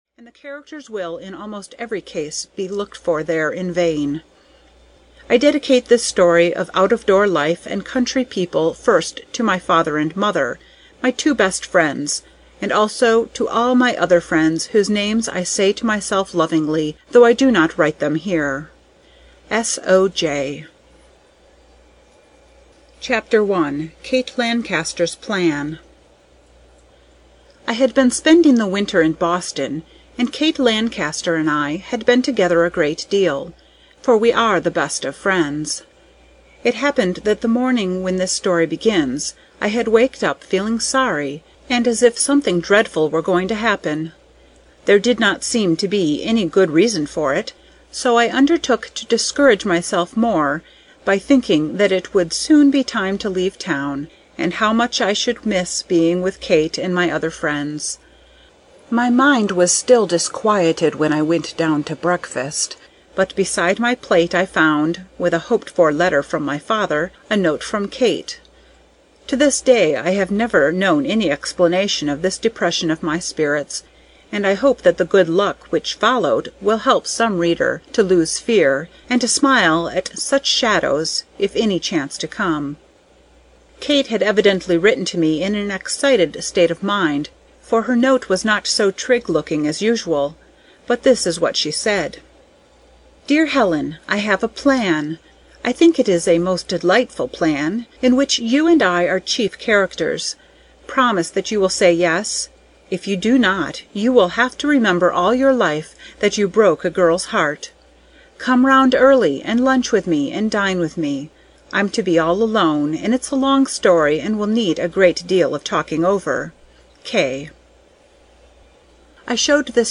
Deephaven (EN) audiokniha
Ukázka z knihy